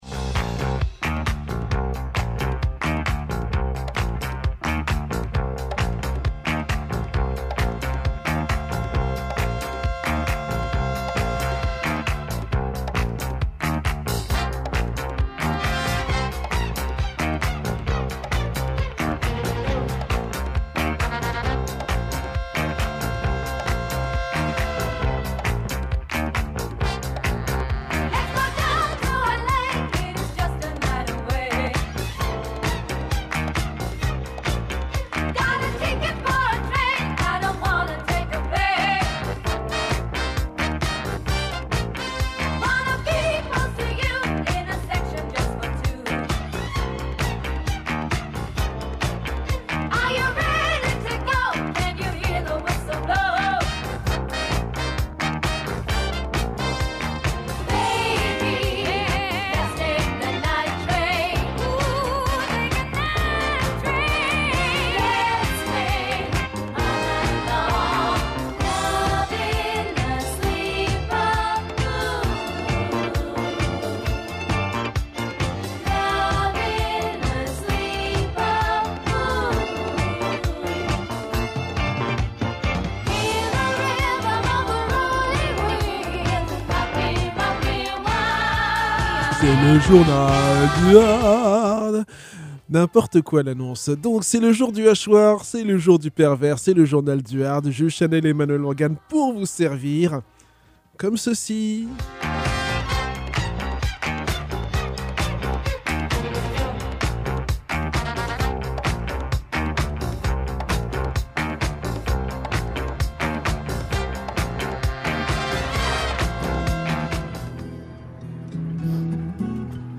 nouvel épisode oscillant entre le hardcore punk et la Oi.